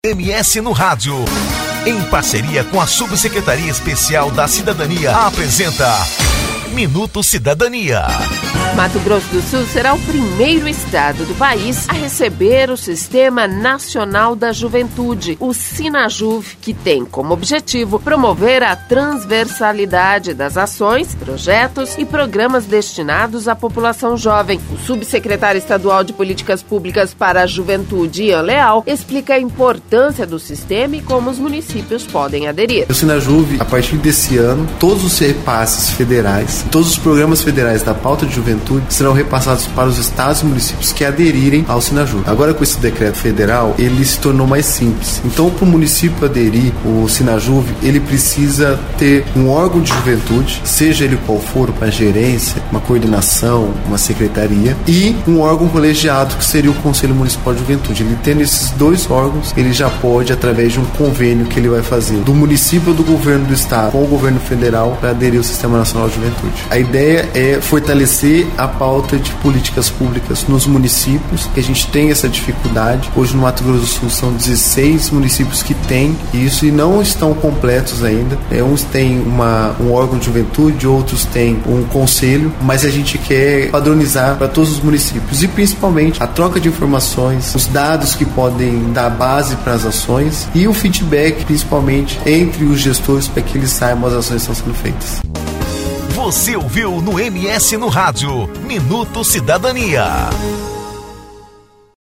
Campo Grande (MS) - Mato Grosso do Sul será o primeiro estado do país a receber o Sistema Nacional da Juventude (Sinajuve). Que tem como objetivo promover a transversalidade das ações, projetos e programas destinados à população jovem. O Subsecretário Estadual de Políticas Públicas para Juventude, Ian Leal, explica a importância do sistema e como os municípios podem aderir.